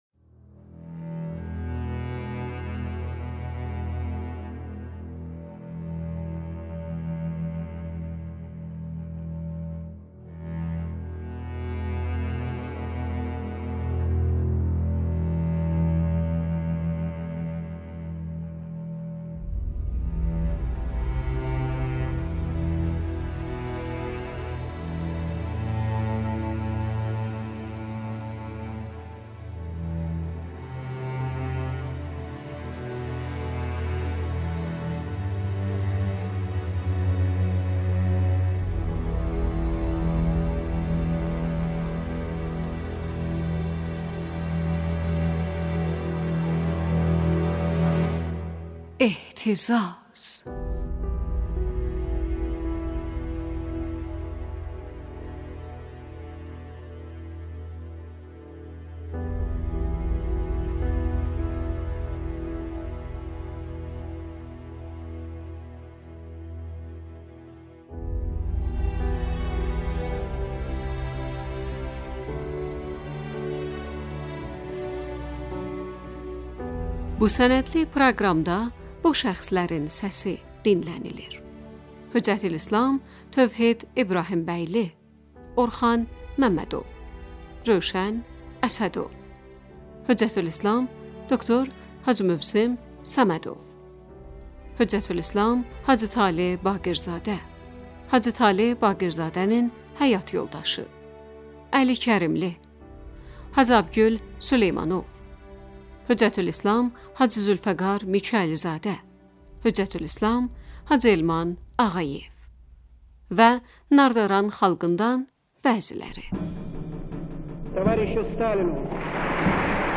Radio sənədli Ehtizaz proqramı, Azərbaycan Respublikasındakı İslam hərəkatını araşdırır.